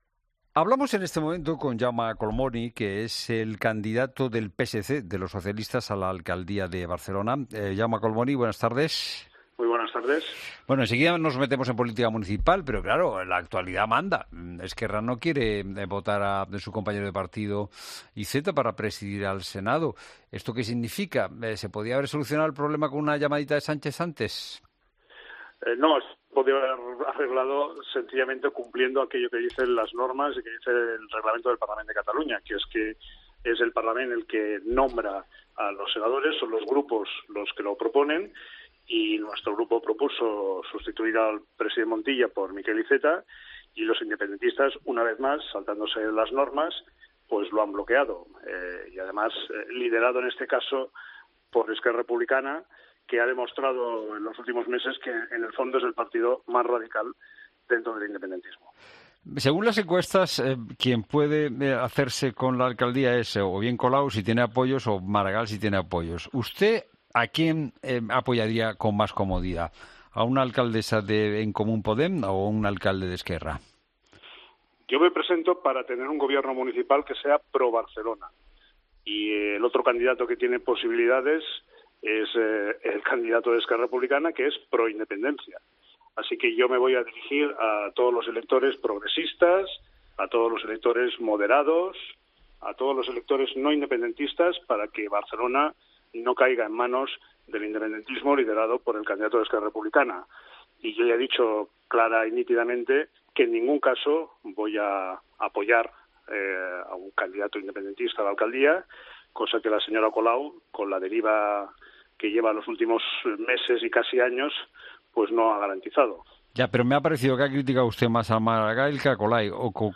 Jaume Collboni, candidato del PSC al Ayuntamiento de Barcelona, en La Tarde
"Me voy a dirigir a todos los sectores no independentistas. En ningún caso voy a apoyar a ningún candidato independentista a la alcaldía", ha insistido Collboni en los micrófonos de COPE.